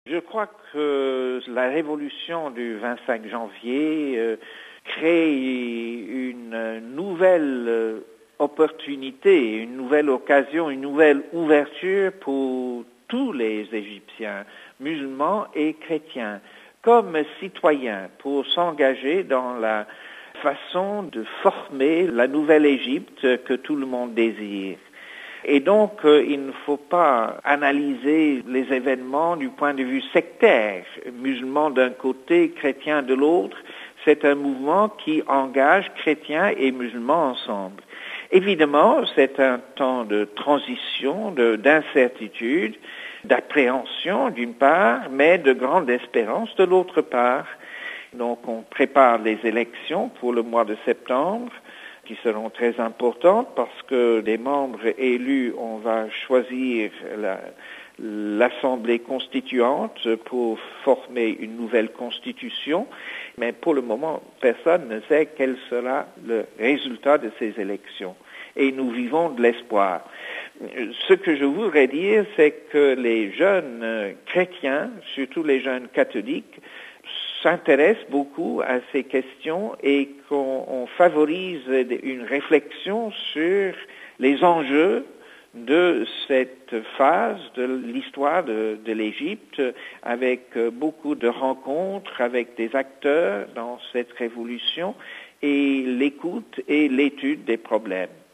Entretien avec le nonce apostolique au Caire